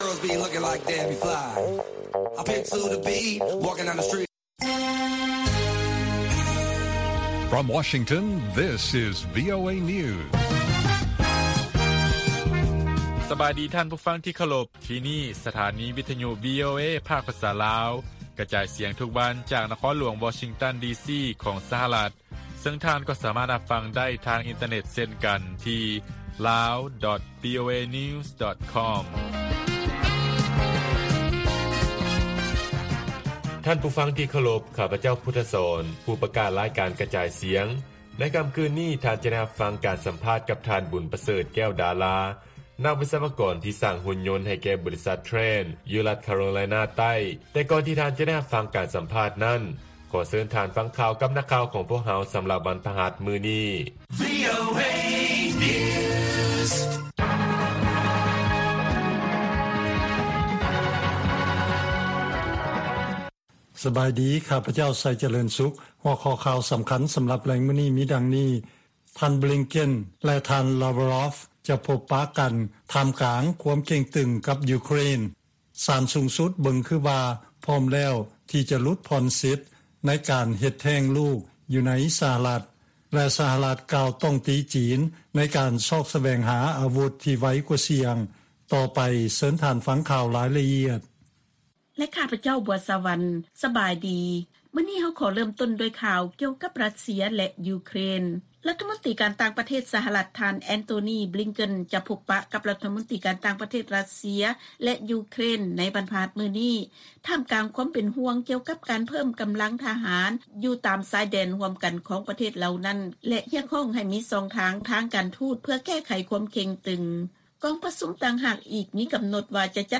ລາຍການກະຈາຍສຽງຂອງວີໂອເອ ລາວ: ການສະຫລອງວັນຊາດລາວ ທີ 2 ທັນວາ ປີນີ້ ມີທັງສິ່ງທີ່ໜ້າຕື່ນເຕັ້ນ ແລະ ສິ່ງທີ່ໜ້າເປັນຫ່ວງ
ວີໂອເອພາກພາສາລາວ ກະຈາຍສຽງທຸກໆວັນ.